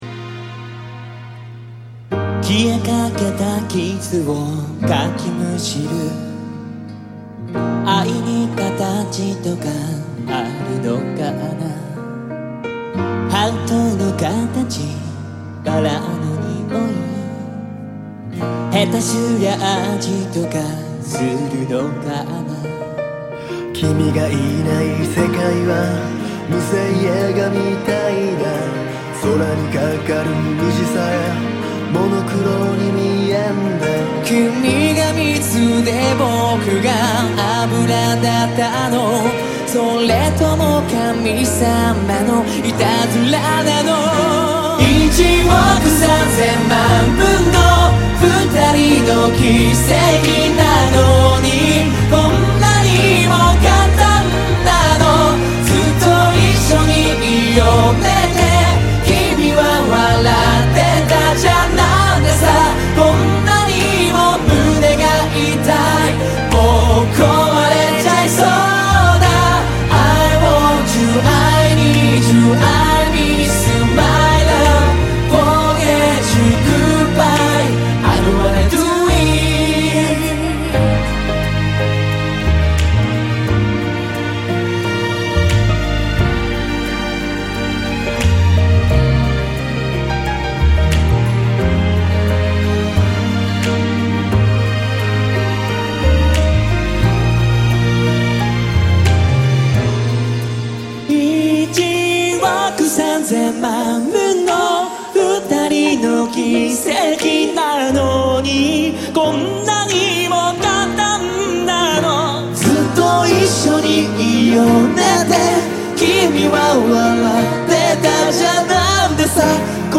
bringing back this beautiful live